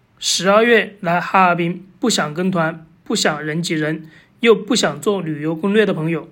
Professionell lokal reseledare röst AI
Ge liv åt ditt reseinnehåll med en varm, kunnig och autentisk lokal röst designad för uppslukande berättande och guidade turer.
Text-till-tal
Varm berättarröst
Med naturlig rytm och expressiv intonation ger den en människoliknande upplevelse som bygger förtroende hos resenärer.